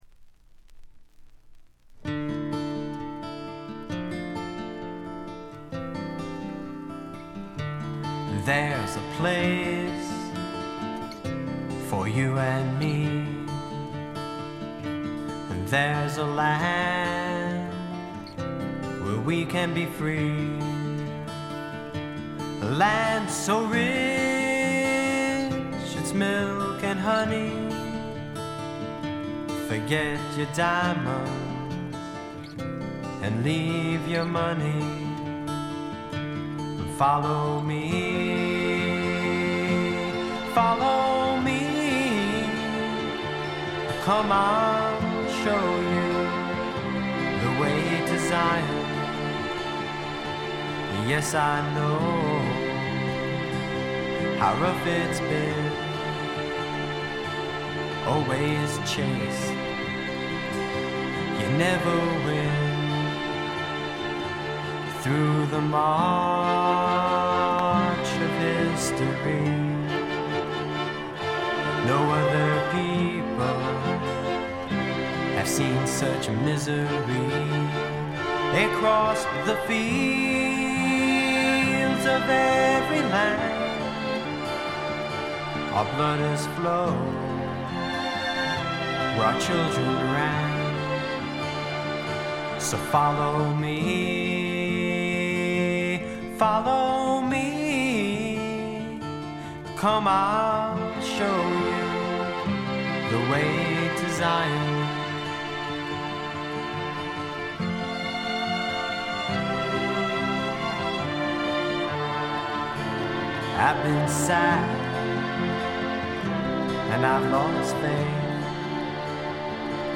ほとんどノイズ感無し。
演奏、歌ともに実にしっかりとしていてメジャー級の85点作品。
試聴曲は現品からの取り込み音源です。